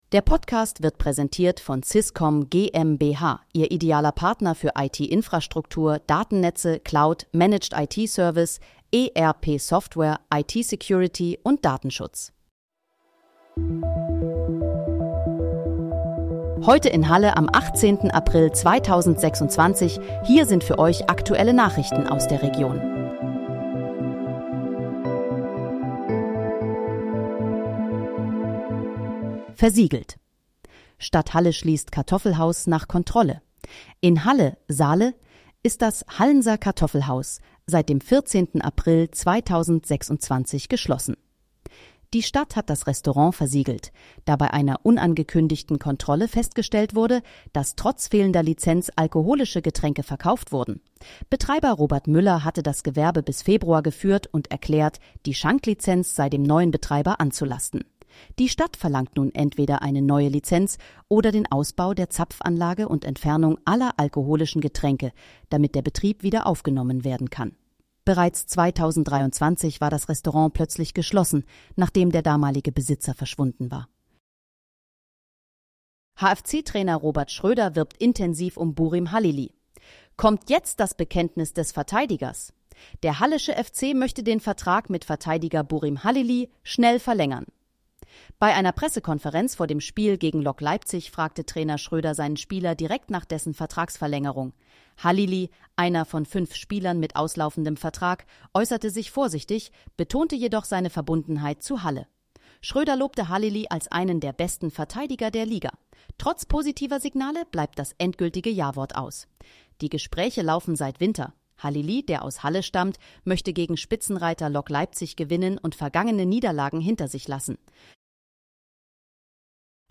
Heute in, Halle: Aktuelle Nachrichten vom 18.04.2026, erstellt mit KI-Unterstützung